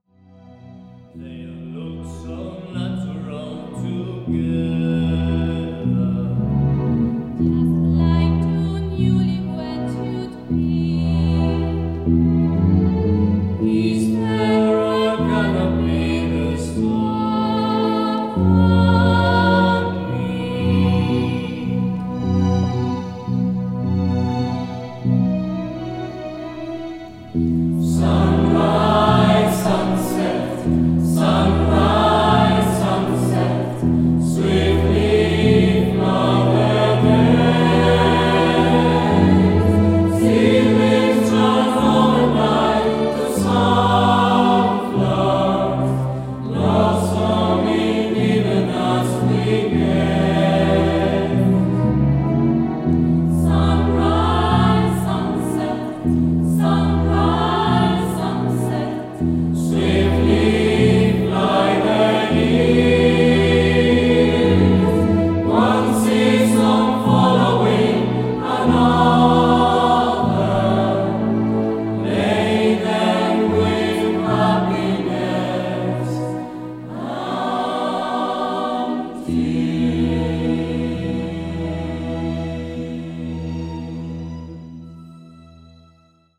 Audios en directo (concierto de estreno,  2 de Diciembre de 2017):
Dependiendo del estilo y del tema concreto, cantamos a capella o con instrumentación de acompañamiento opcional (teclado).